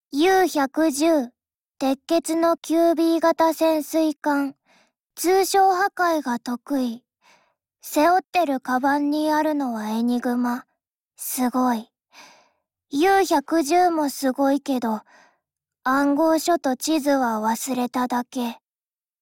舰船台词
自我介绍